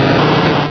Cri de Saquedeneu dans Pokémon Rubis et Saphir.